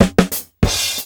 FILL 11   -R.wav